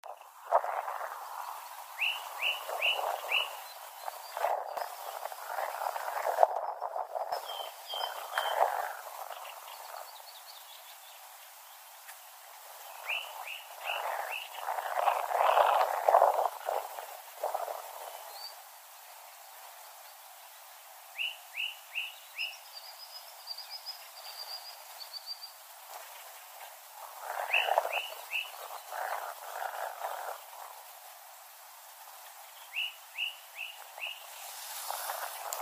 Deze keer heb ik de dicteer-app van de telefoon gebruikt en vanwege de rust die er heerste en de rotswand waartegen het geluid leek te weerkaatsen, kwam het geluid er nog best aardig op. Thuis heb ik het later nog wat bewerkt door de wind te dempen en het verdere geluid wat te versterken en daarmee kreeg ik mijn waarnemingen van de Beflijster compleet.
De zang van de Beflijster!
Beflijster2.mp3